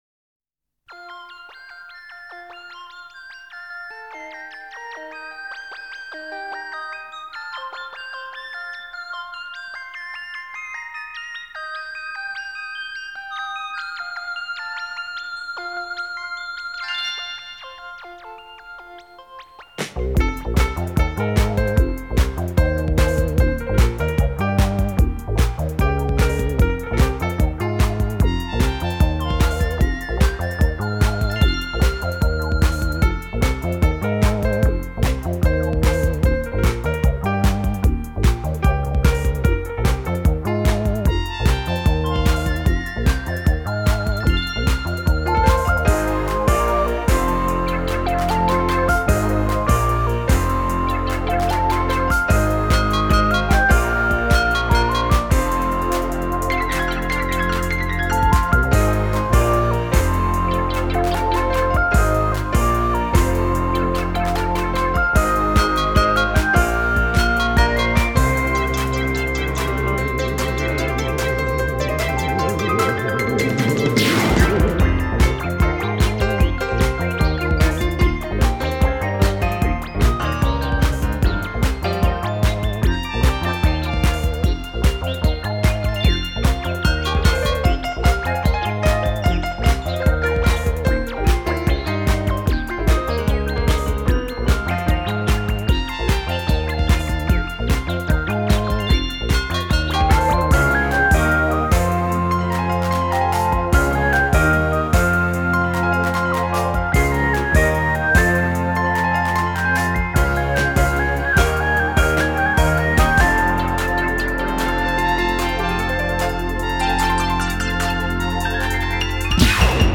這是一張由鋼琴主奏 魔音琴協奏的演奏曲的唱片
淙淙流水般的音樂保證令您激賞不已 且它不會有一般電子音樂那般的生硬